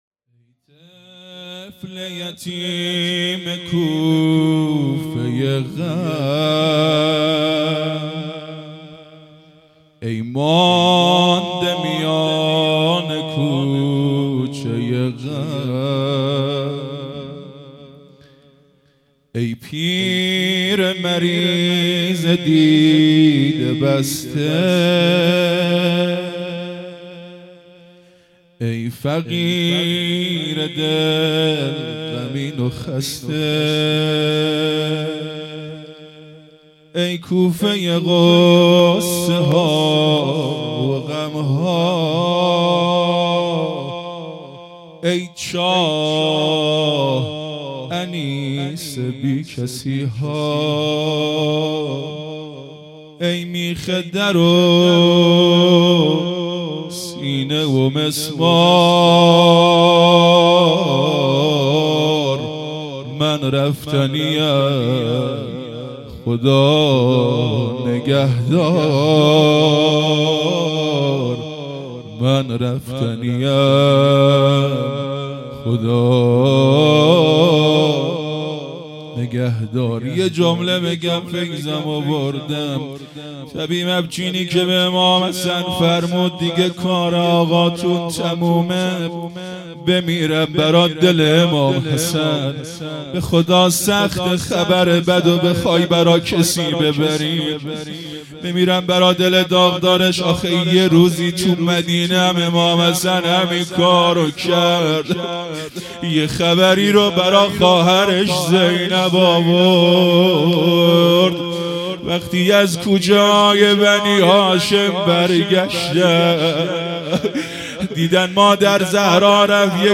مرثیه خوانی|طفل یتیم کوفه غم
هیئت مکتب الزهرا(س)دارالعباده یزد
شبهای قدر ۱۰۴۲ رمضان ۱۴۴۴ شب بیست و یکم